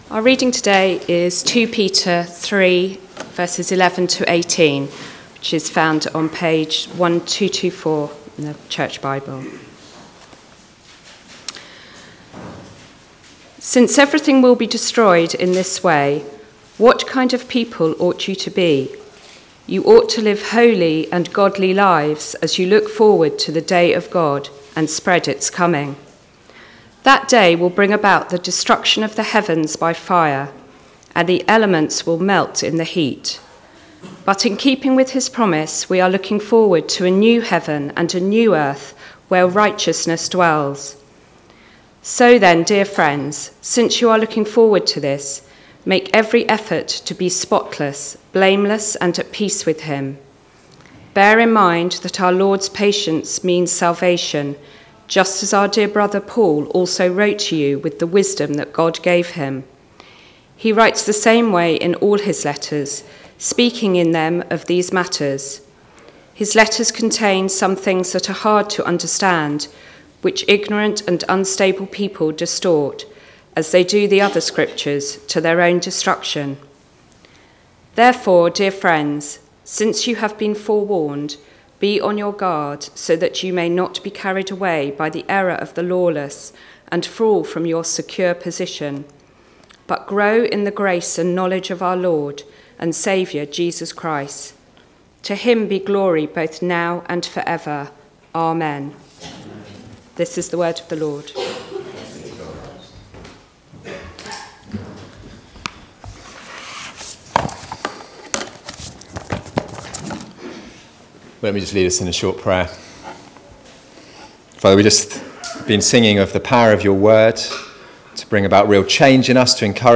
Remember These Things Passage: 2 Peter 3:11-18 Service Type: Weekly Service at 4pm « Remember